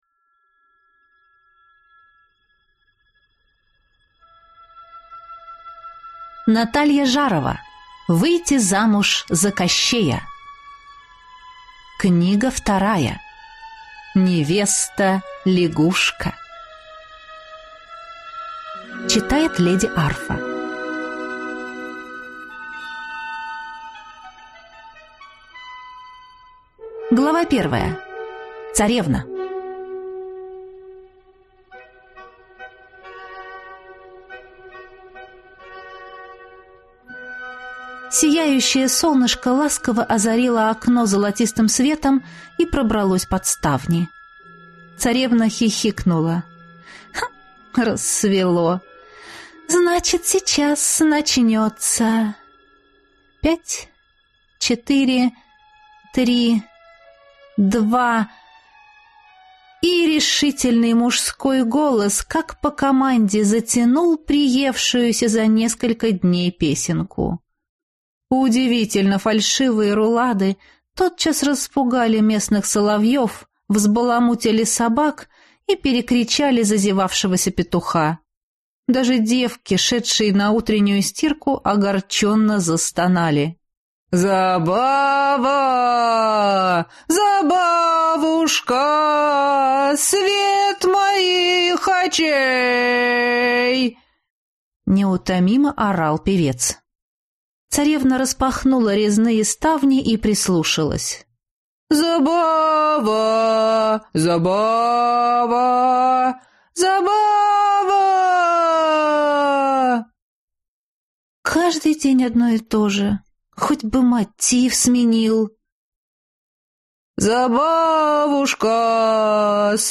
Аудиокнига Выйти замуж за Кощея. Невеста-лягушка | Библиотека аудиокниг